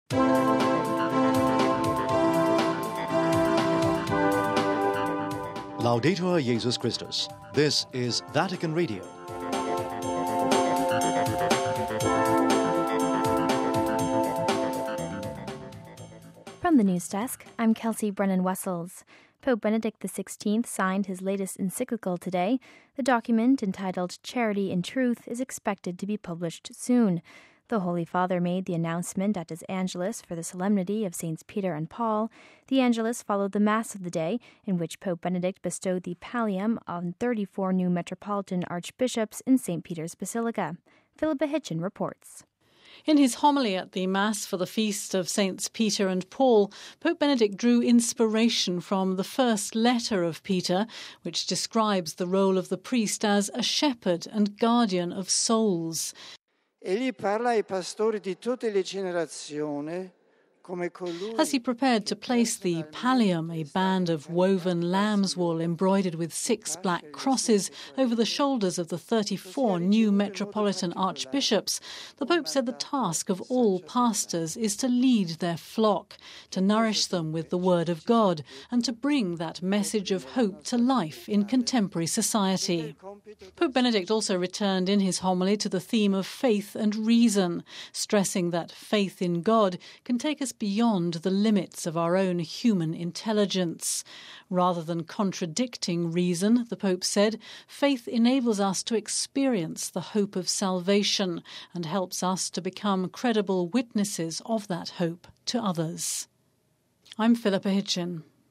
We have this report: RealAudio